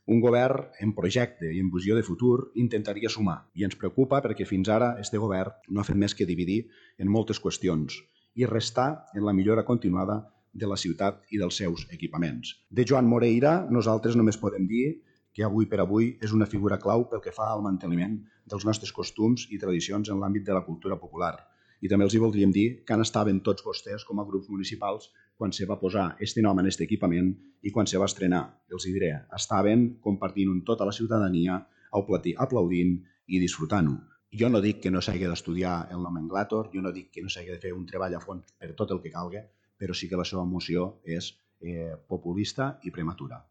El regidor Domingo tomàs ha defensat la figura de Moreira com a clau per al manteniment de la tradició cultural i el folklore tortosí. Tomàs ha qualificat la moció de populista…